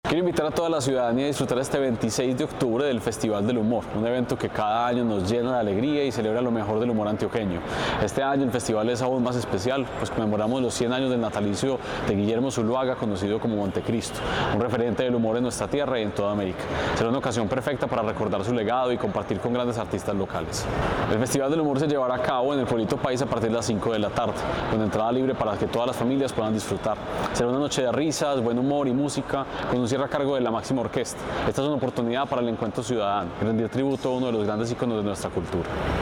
Palabras de Santiago Silva, Secretario de Cultura